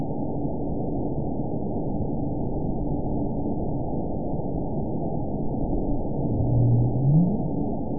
event 913868 date 04/23/22 time 00:18:10 GMT (3 years, 1 month ago) score 9.18 location TSS-AB01 detected by nrw target species NRW annotations +NRW Spectrogram: Frequency (kHz) vs. Time (s) audio not available .wav